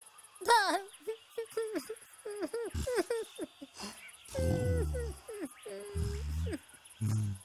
Play Animanimals Elephant Trunk Crying 2 - SoundBoardGuy
animanimals-elephant_ox5vdsru.mp3